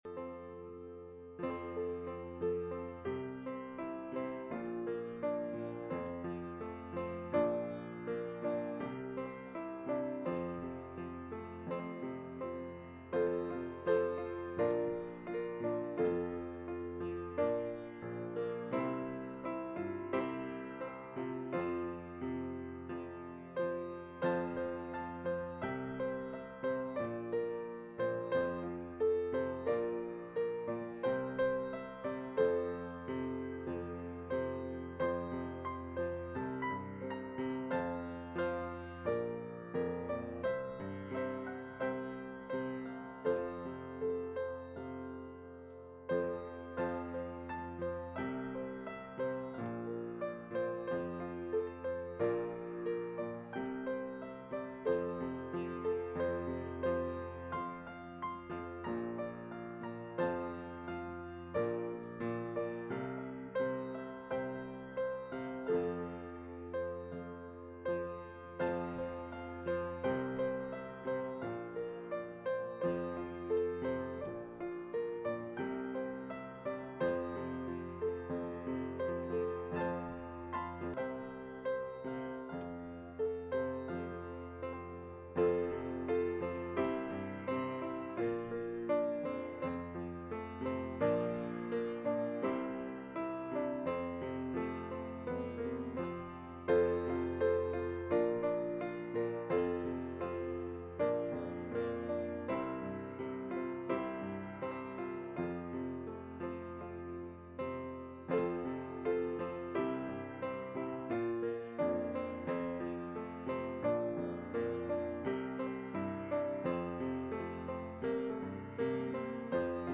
Sunday School Christmas Program